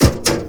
ladmetal1.wav